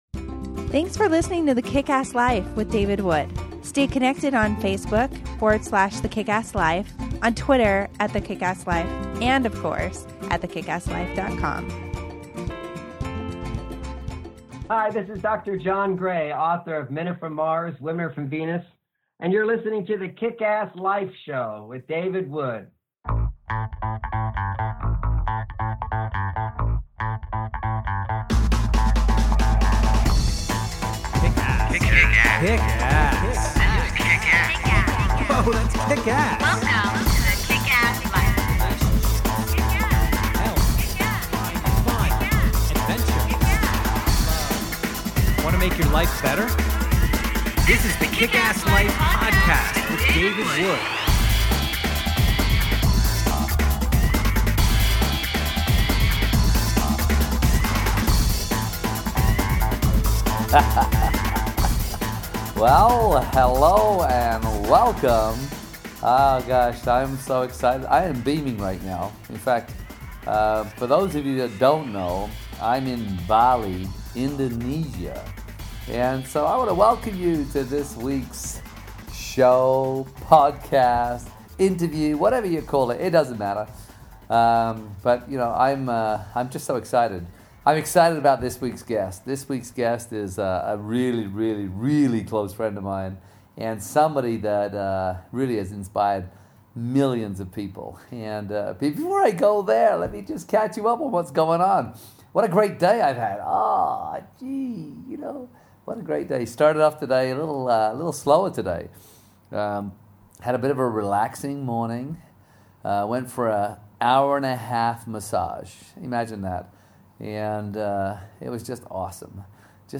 Interview on I'm Awake! Now What?